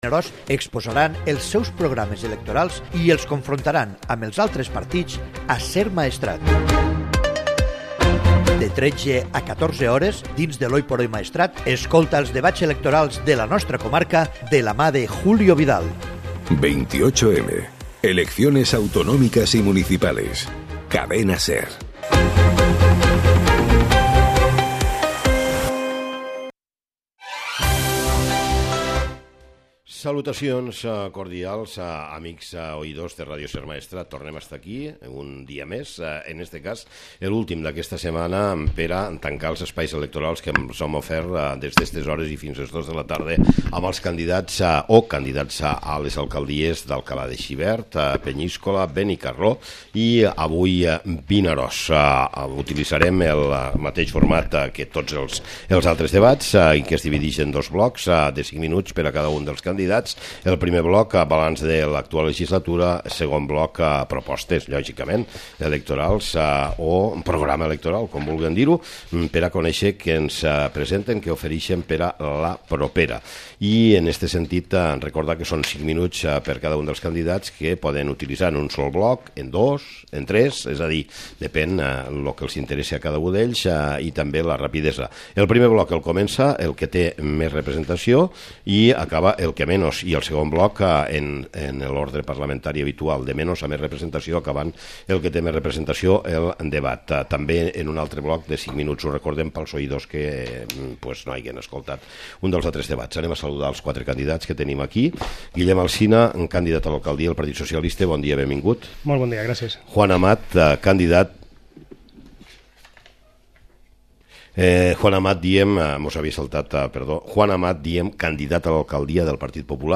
Debat de candidats a l'alcaldia de Vinaròs